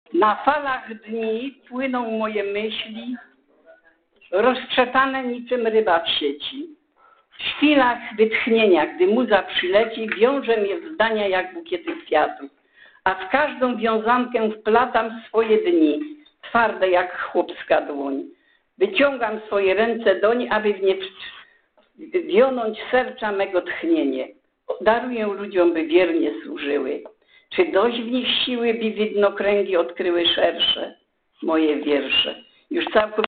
w siedzibie TMZŻ